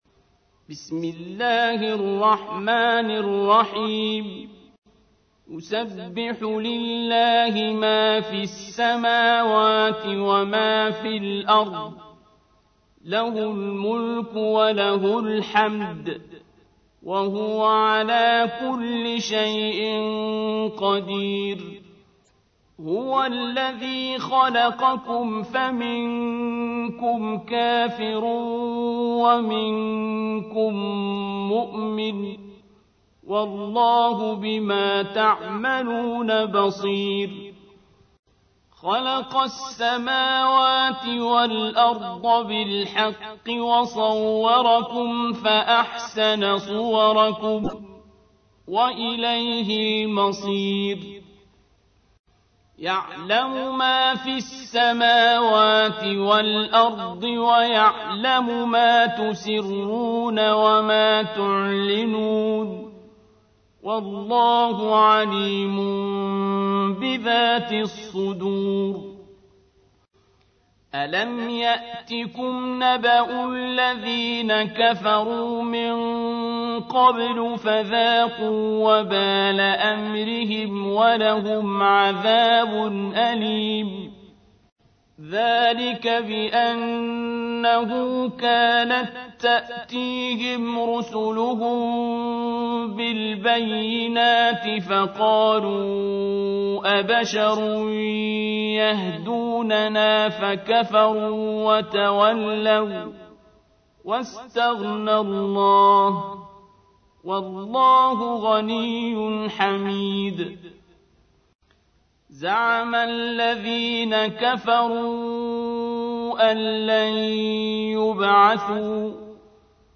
تحميل : 64. سورة التغابن / القارئ عبد الباسط عبد الصمد / القرآن الكريم / موقع يا حسين